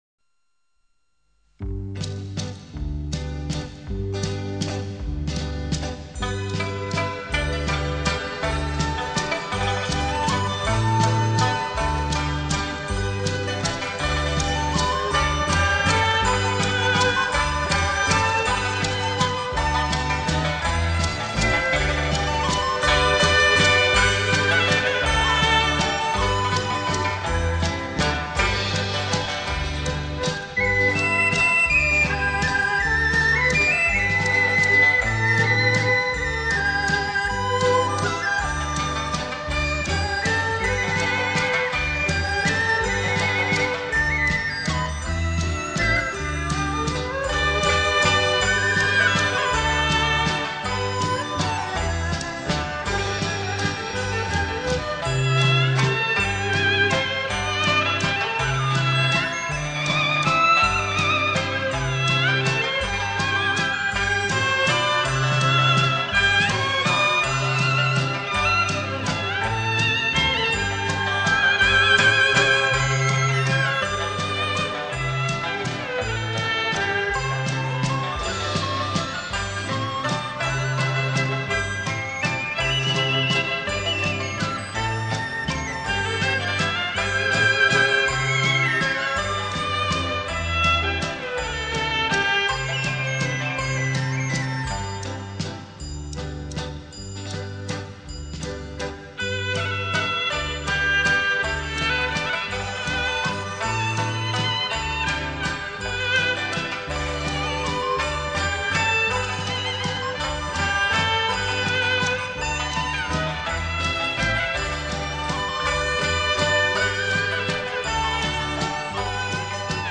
[24/6/2011]再求一首“广东音乐”——仙女牧羊 激动社区，陪你一起慢慢变老！